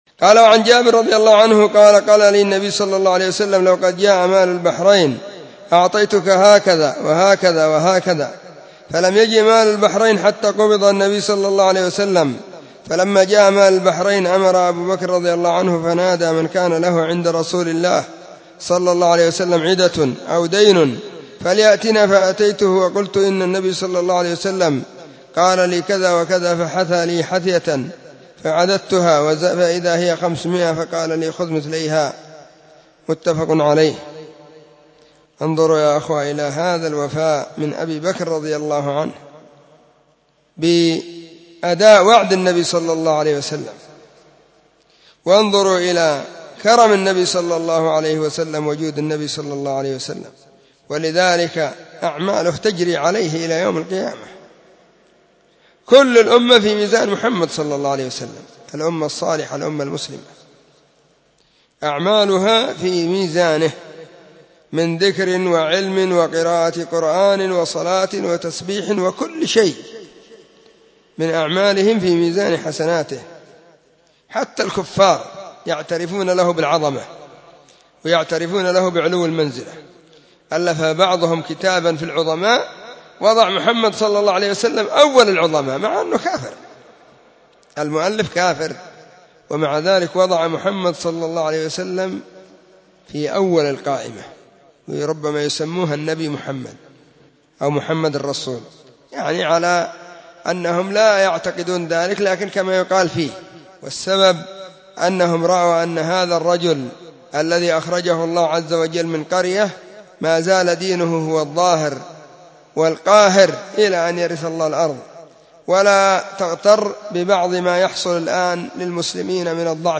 📢 مسجد الصحابة – بالغيضة – المهرة – اليمن حرسها الله.
الجمعة 19 محرم 1443 هــــ | الردود الصوتية | شارك بتعليقك